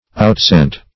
Outscent \Out*scent"\
outscent.mp3